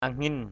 /a-ŋɪn/